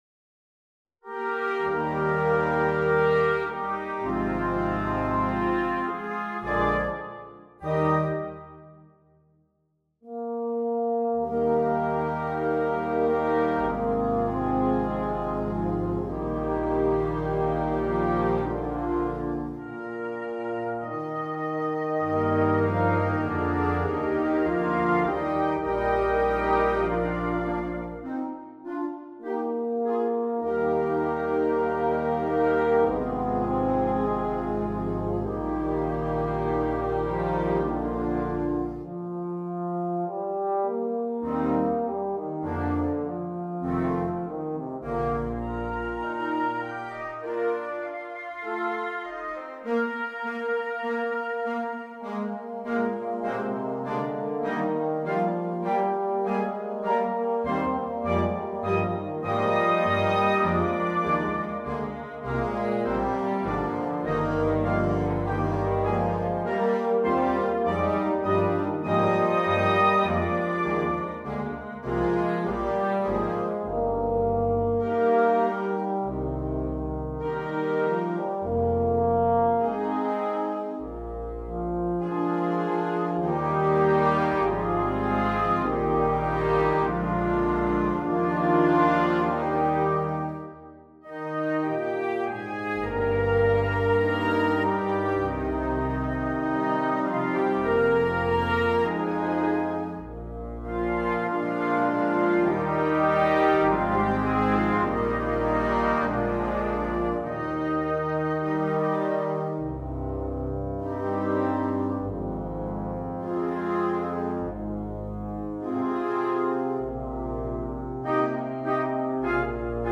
2. Blasorchester
mit Soloinstrument
Euphonium (Solo), Euphonium in C – Bass-Schlüssel (Solo)
Klassik